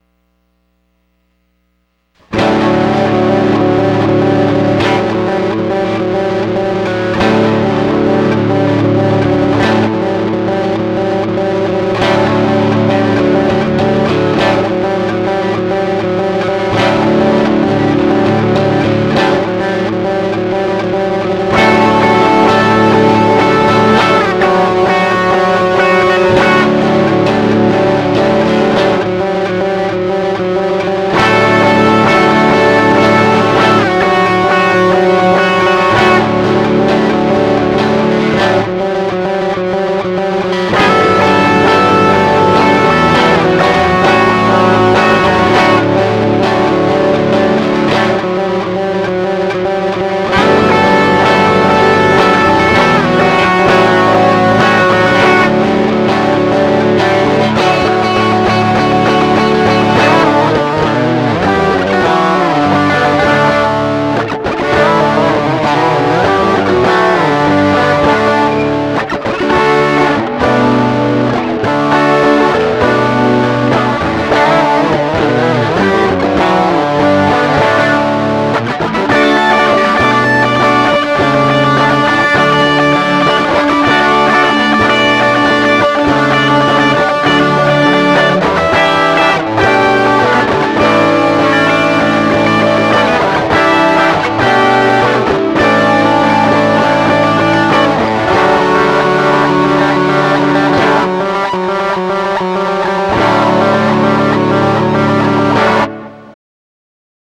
For this one make sure your device volume is lowered, I came across it in my files which I completely forgot about. It's an idea that started with that with that sort of looping guitar in the beginning (ends with it too) and got turned into a demo as homage to my rock days, a few years back.